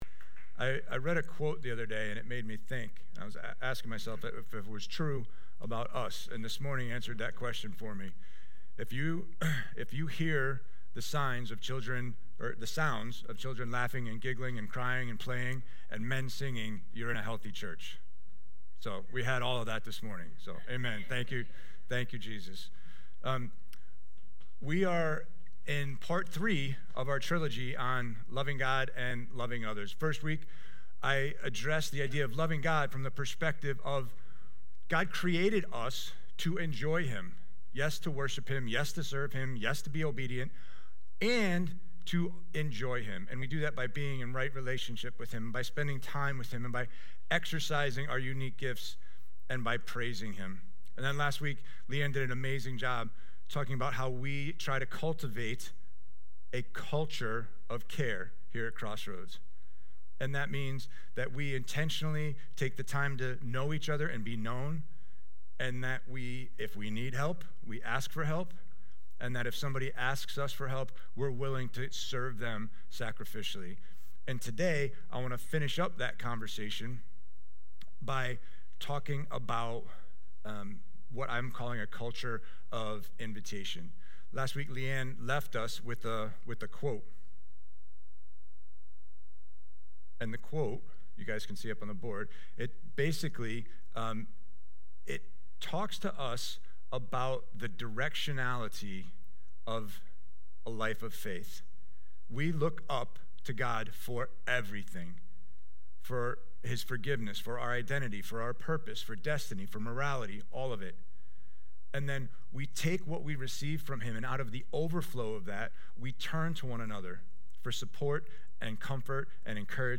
Today we talk about having a culture of invitation. Timestamps: Welcome: 1:58 Invitation to Prayer: 2:10 Worship: 8:04 Sermon: 21:10 Offering & Announcements: 45:49 Songlist: Open Up the Heavens 10,000 Reasons (Bless the Lord) I’m So Blessed